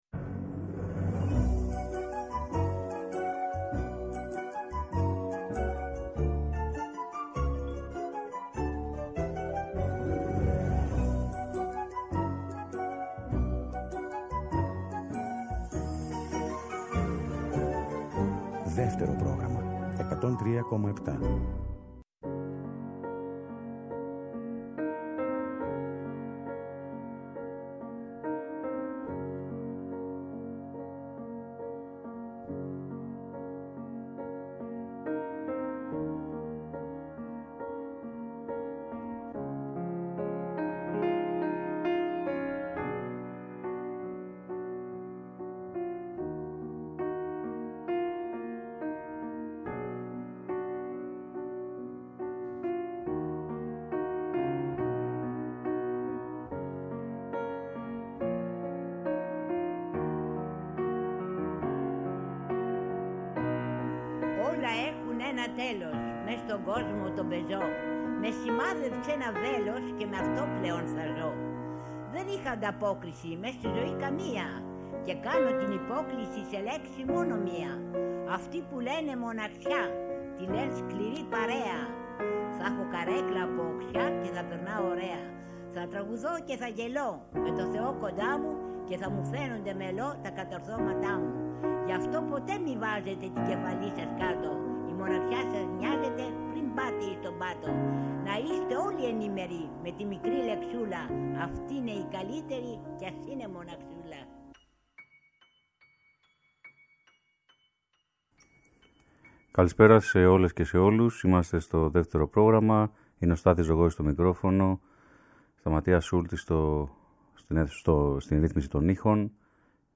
Τραγούδια διαχρονικά, παραδοσιακά και μικρασιάτικα στο Δεύτερο Πρόγραμμα 103.7